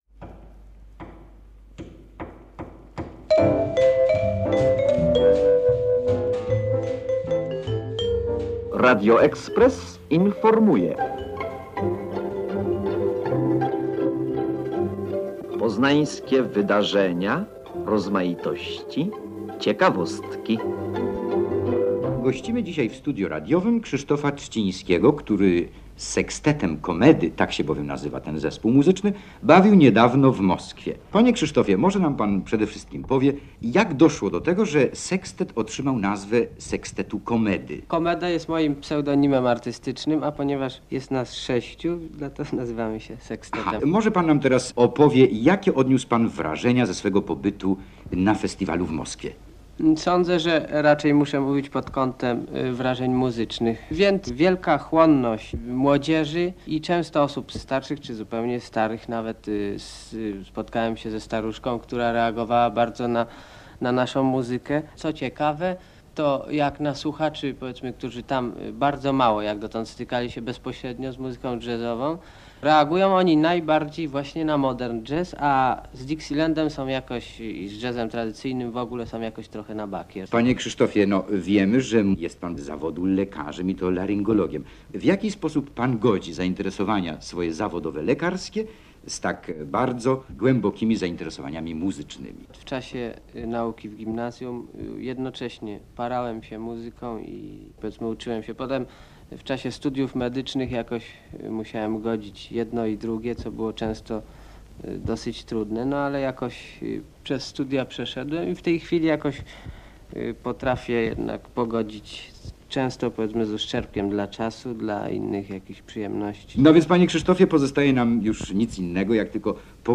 Komeda - reportaż